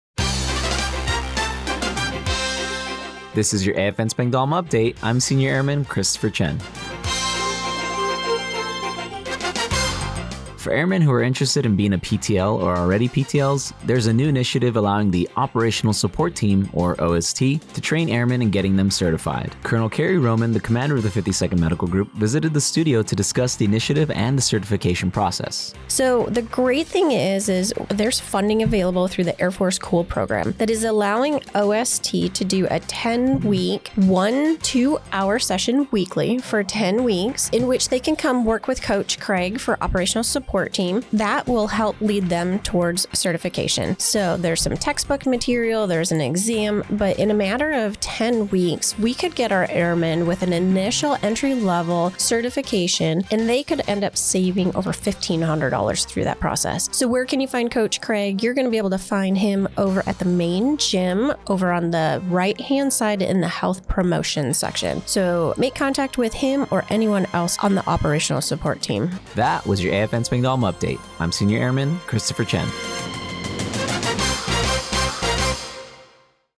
The following was the radio news report for AFN Spangdahlem for Oct. 25, 2024.